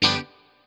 CHORD 7   AB.wav